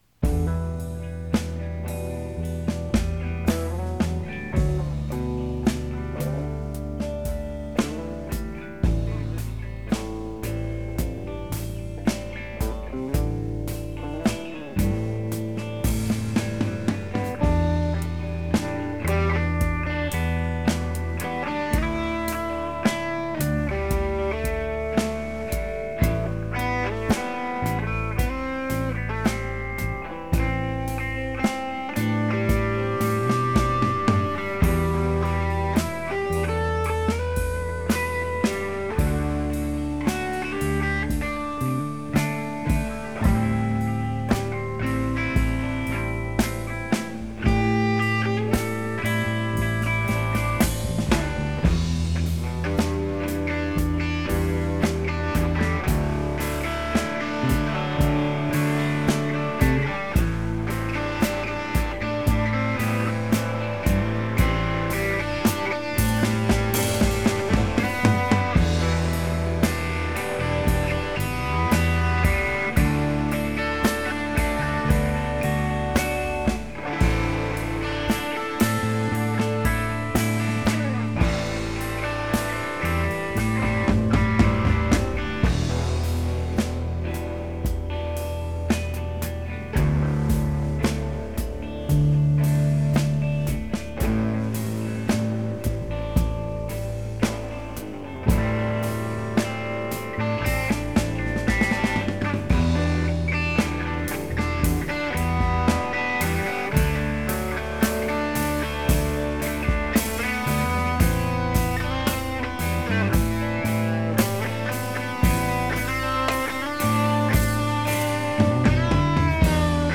rock music Blues Rock